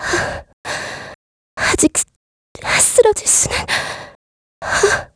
Ripine-Vox_Dead_kr.wav